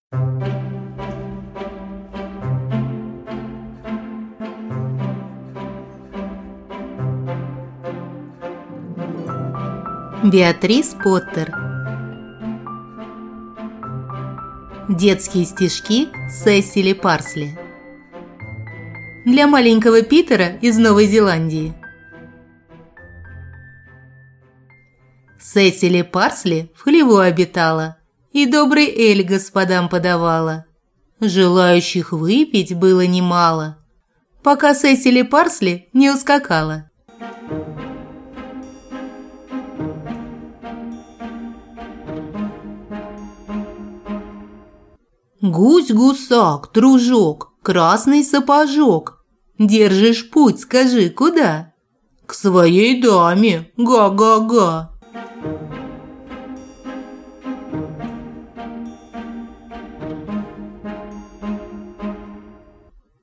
Аудиокнига Детские стишки Сесили Парсли | Библиотека аудиокниг